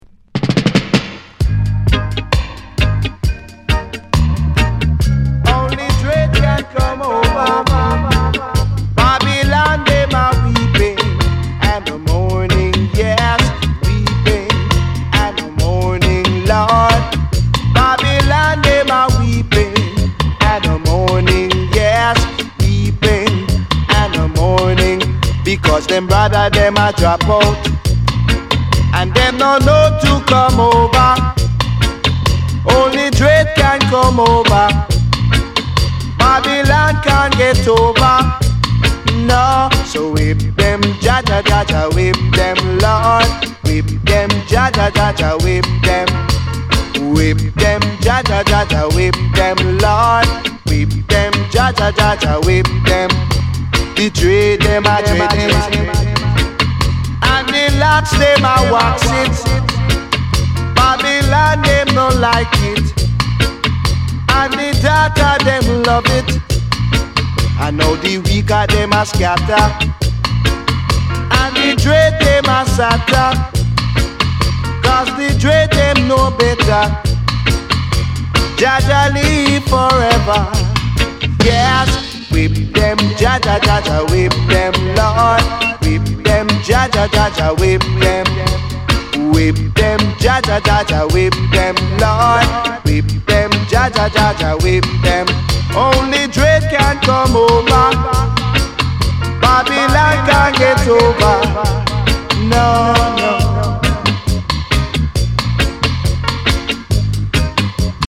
スウィートな中にも熱さが光る素晴らしい内容です。